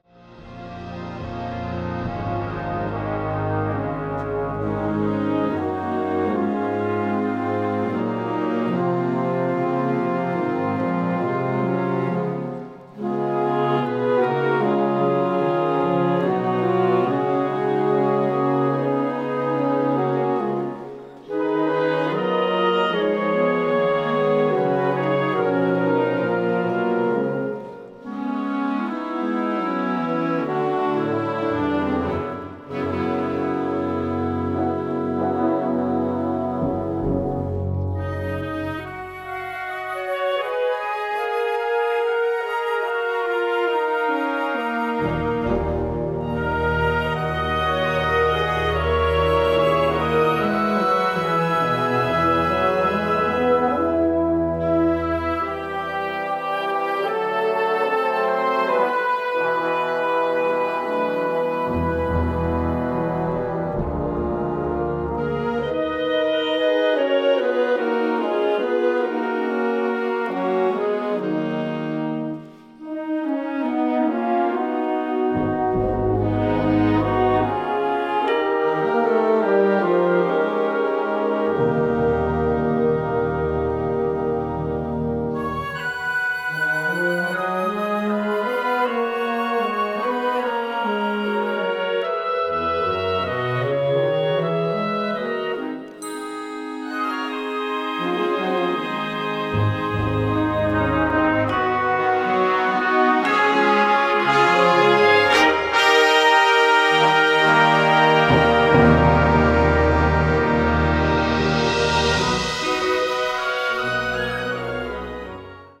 A Hymn for Band